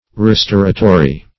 Restoratory \Re*stor"a*to*ry\ (r?*st?r"?*t?*r?), a.
restoratory.mp3